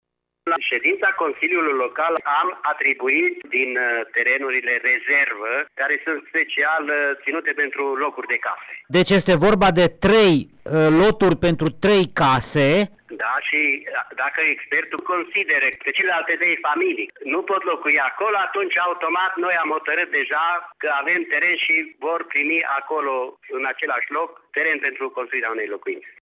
Primarul comunei Apold, Toth Janos, a mai spus că dacă celelalte 3 case afectate, rămase în picioare, se va dovedi că sunt fisurate, și acele familii vor primi terenuri.